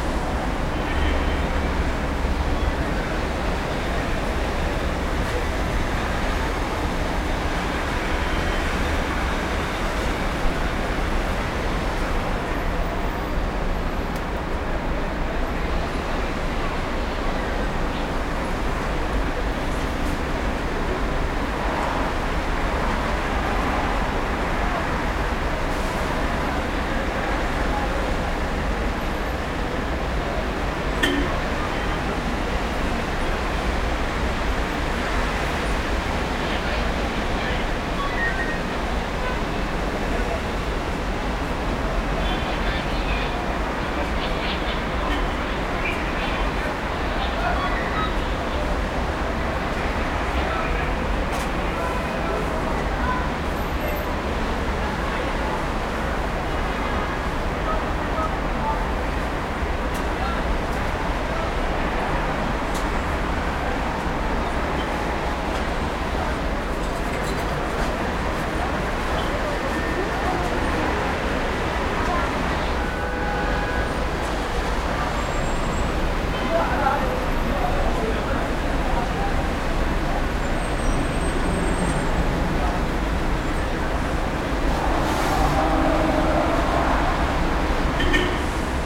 cityLoop.ogg